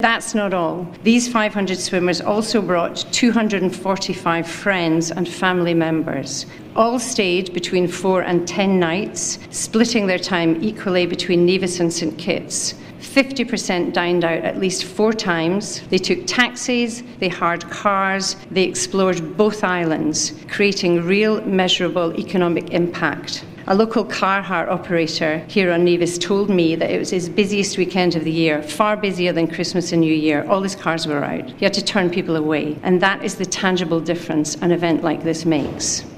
A Media launch was held at Nevis’ Malcolm Guishard Recreational Park at Pinneys for the hosting of the 2026 Nevis to St. Kitts Cross Channel Swim on Friday, January 9th, 2026.